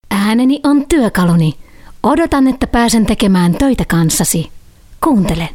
Sprechprobe: Sonstiges (Muttersprache):